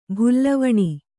♪ bhullavaṇi